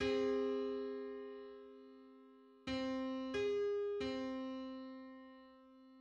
Public domain Public domain false false This media depicts a musical interval outside of a specific musical context.
Three-hundred-ninety-ninth_harmonic_on_C.mid.mp3